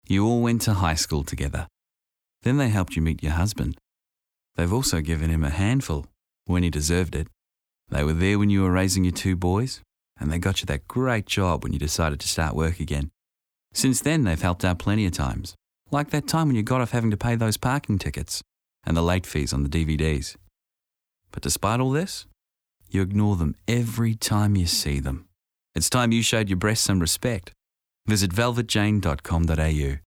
Winning a Gold Radio Lion at Cannes this year for radio specialist Eardrum and Sapient Nitro Brisbane, Earphone Bully is an anti-bullying campaign best listened to with headphones. In a bully scenario, it beats the listener around the head with harsh thuds, cracks and theatre of the mind.